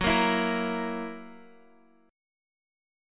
Gb7 chord